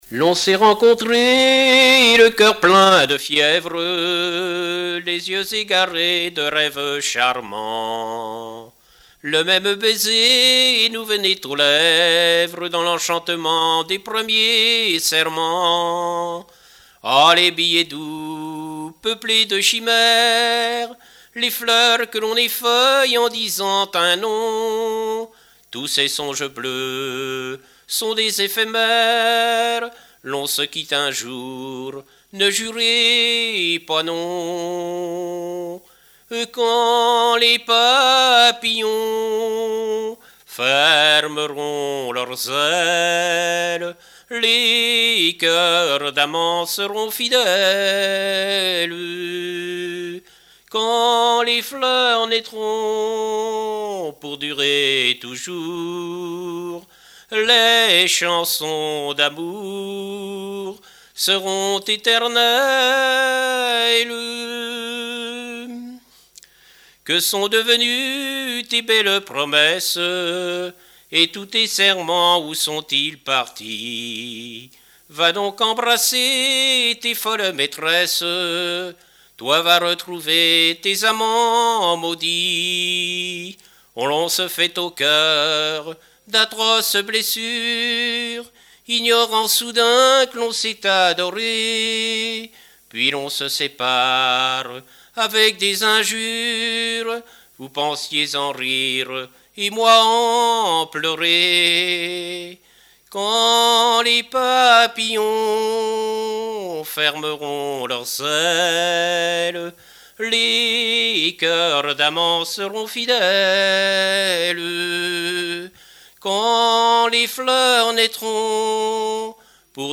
Genre strophique
chansons de variété et music-hall
Pièce musicale inédite